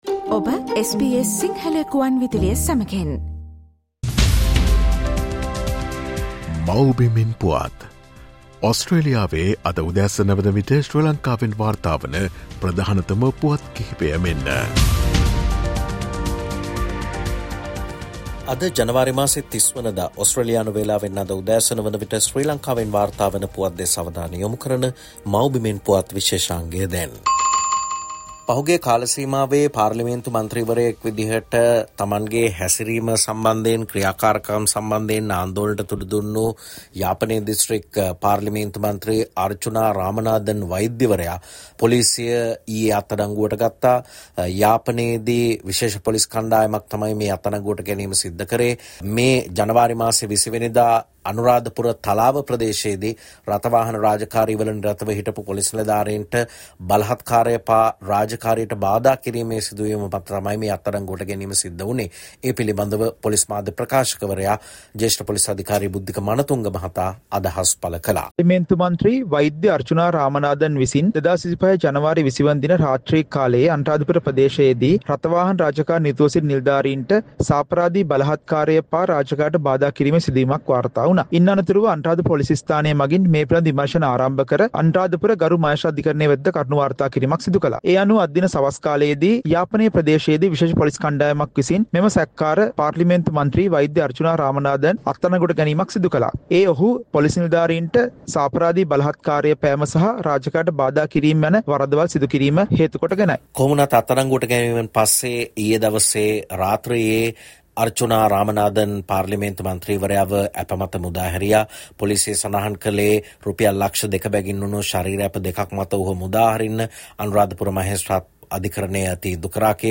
SBS Sinhala reporter and senior journalist